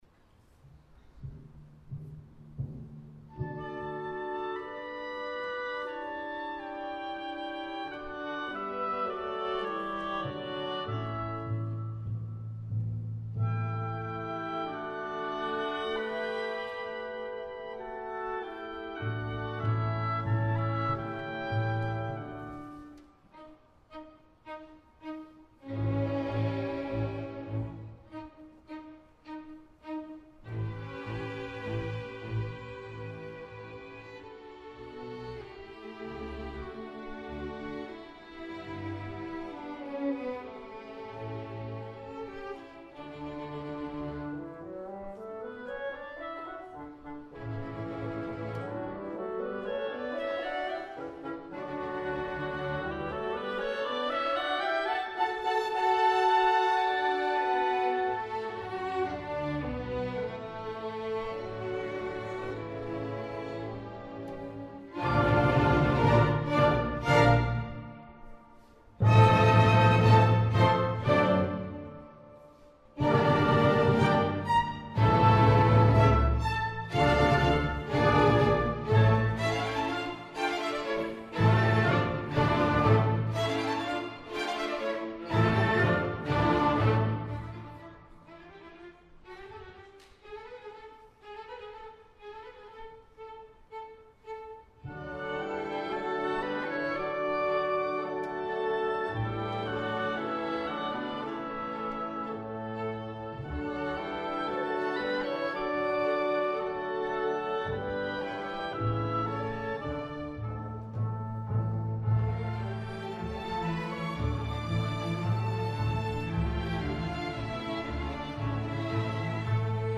Beethoven Violin Concerto — Waltham Symphony Orchestra